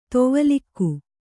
♪ tovalikku